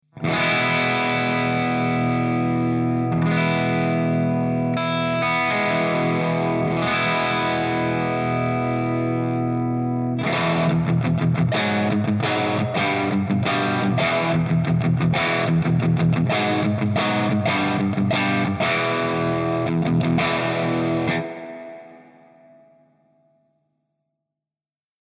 089_PEAVEY_STANDARDREVERB_GB_P90.mp3